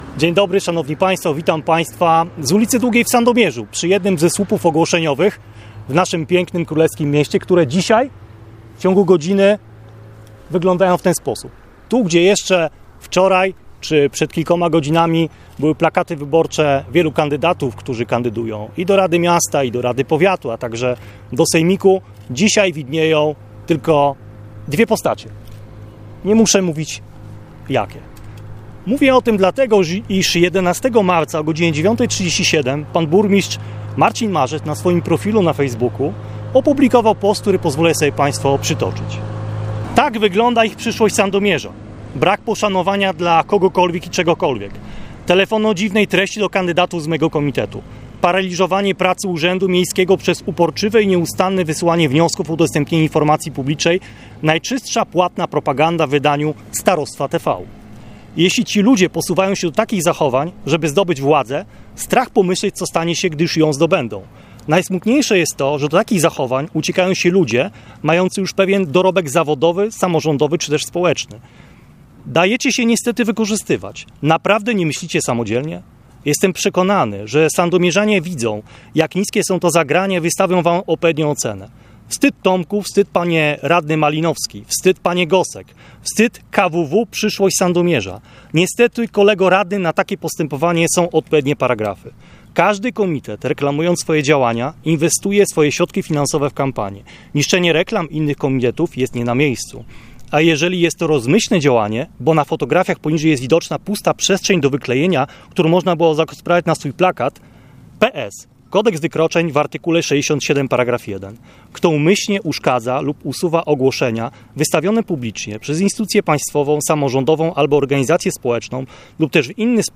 w czasie dzisiejszej konferencji prasowej zapowiedział złożenie apelacji: